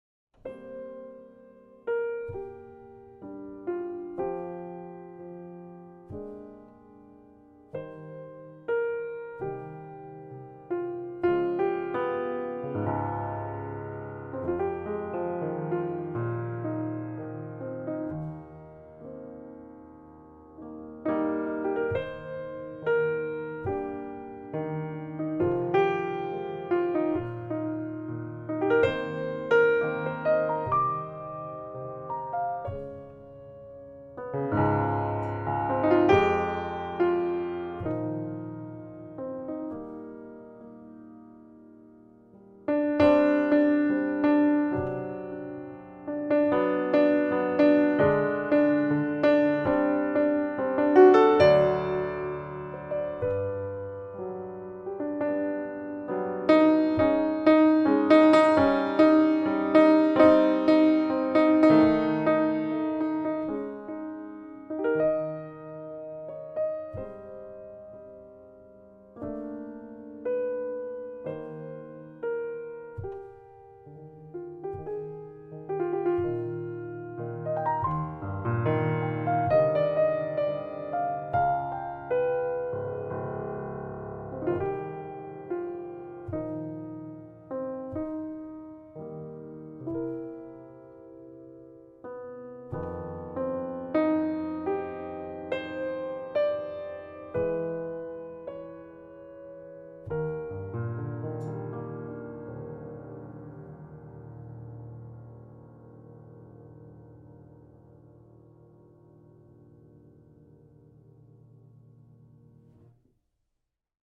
A very fun to play baby grand piano